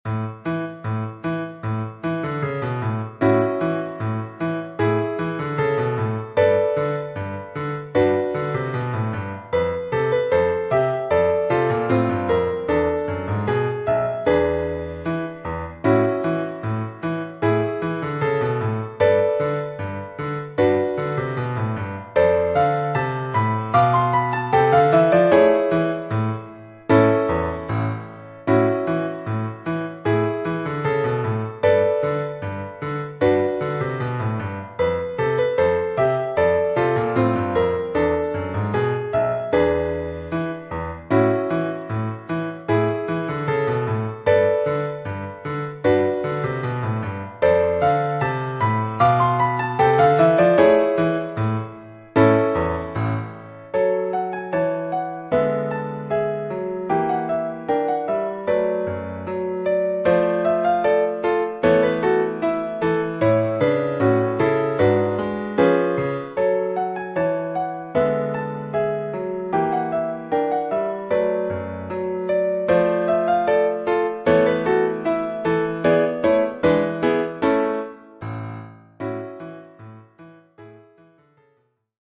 1 titre, piano solo : partie de piano
Oeuvre pour piano solo.